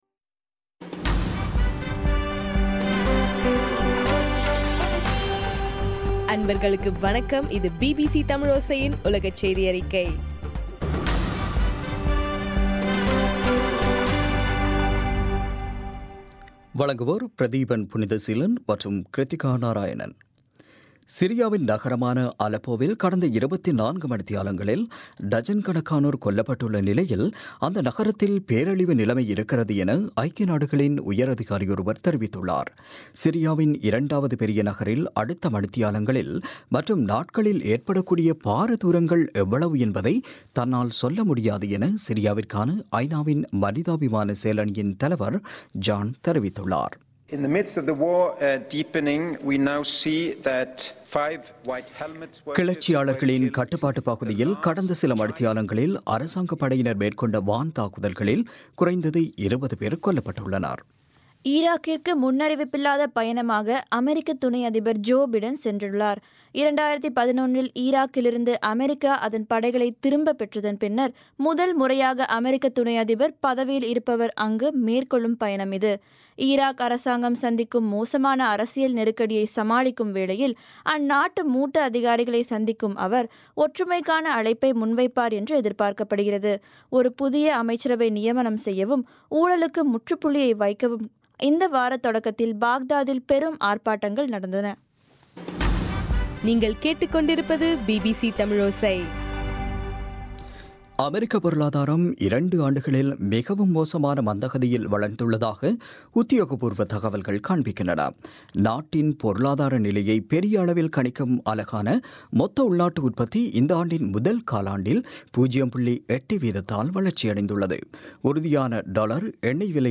ஏப்ரல் 28 பிபிசியின் உலகச் செய்திகள்